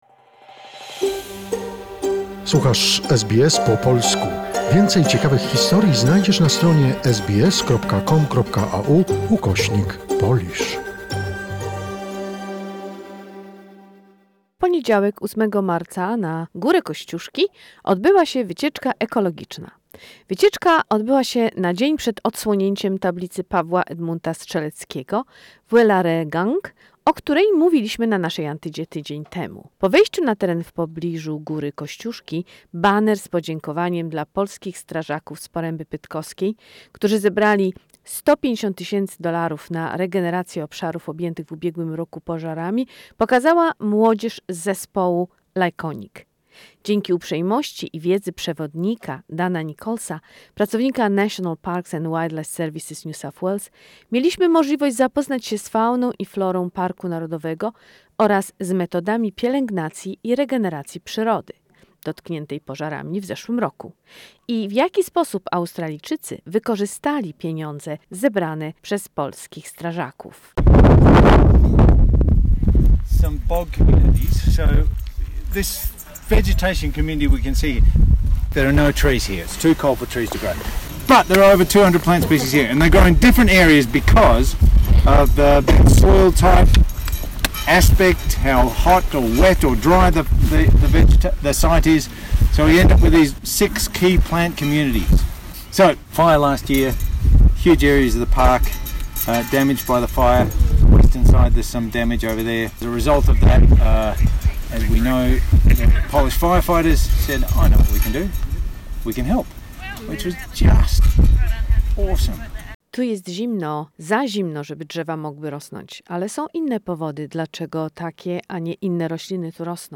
Report from the ecological trip to Kościuszko National Park, the day before the unveiling of the Edmund Paweł Strzelecki memorial plaque in Welaregang.